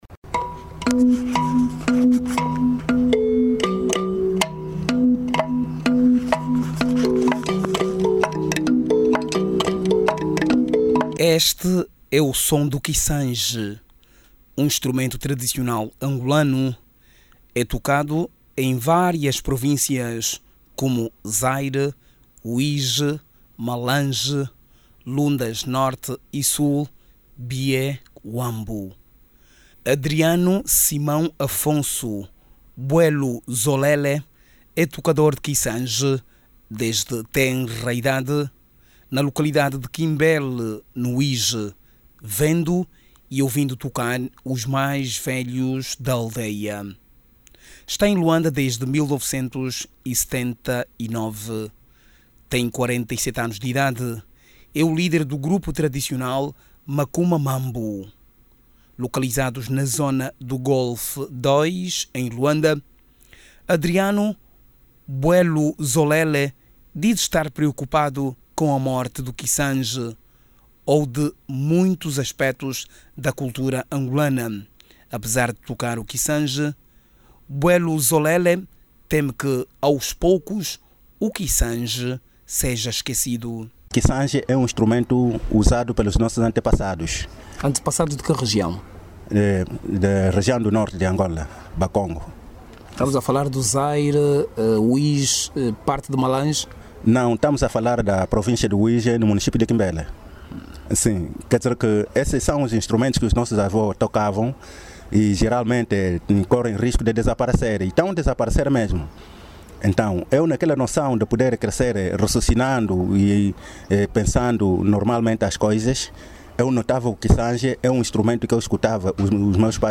Kissanji um instrumento tradicional